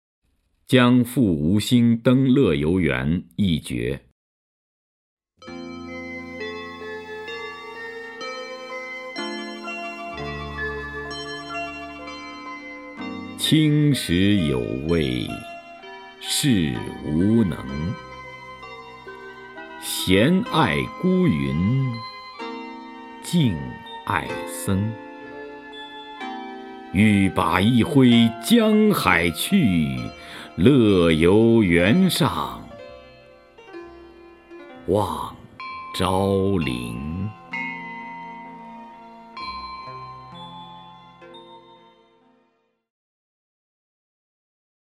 徐涛朗诵：《将赴吴兴登乐游原一绝》(（唐）杜牧) （唐）杜牧 名家朗诵欣赏徐涛 语文PLUS